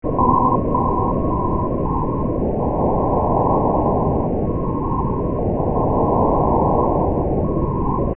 It presents dichotic pitches that move about in virtural auditory space.
Demo 3: Moving Tones
This sequence begins with 2 monaurally audible tones to orient you to the dichotic pitches which follow. The signal and background ITDs change over time, so that each may appear to move laterally through your head.